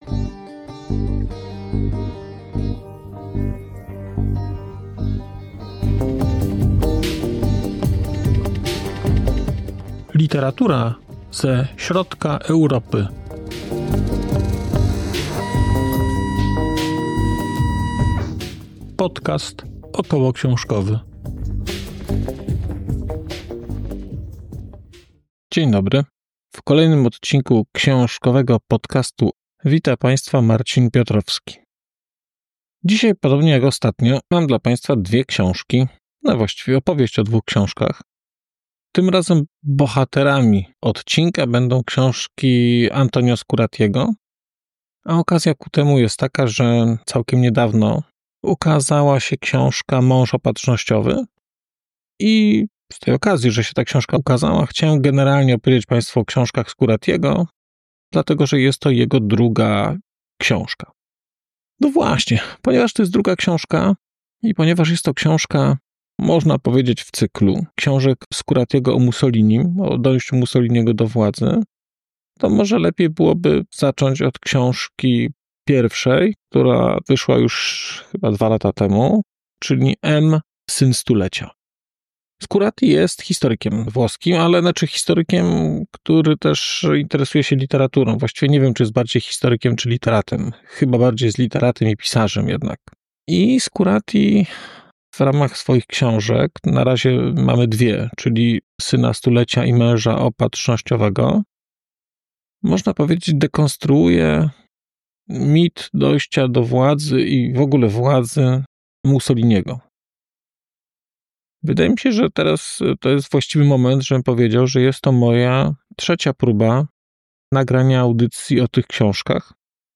🔧 odcinek zremasterowany: 6.04.2025